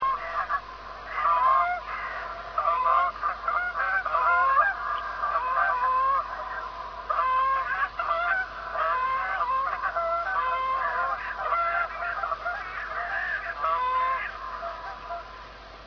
Canada Goose.
A sound familiar to all residents of Knutsford, birdwatchers or not, heard mostly in Winter as flocks move constantly between the local Meres.
canadas.ra